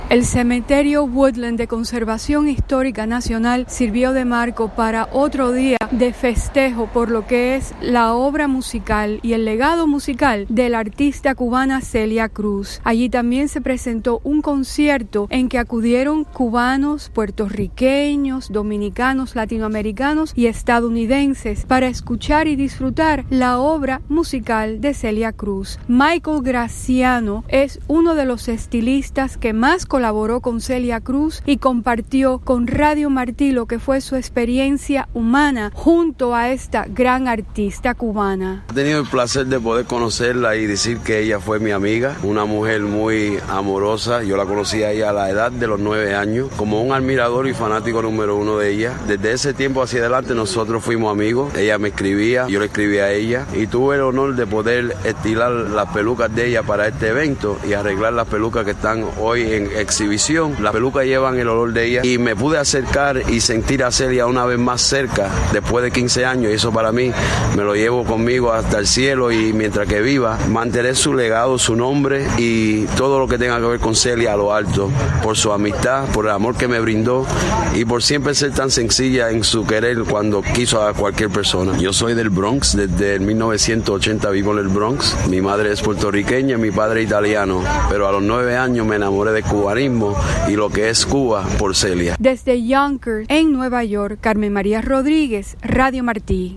Una jornada de tributo a la cantante cubana Celia Cruz por el 15 aniversario de su desaparición física concluyó este domingo en el cementerio Woodlawn, en la ciudad de Nueva York, donde reposan sus restos y se preserva su memoria.